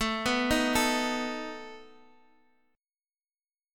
Asus2b5 Chord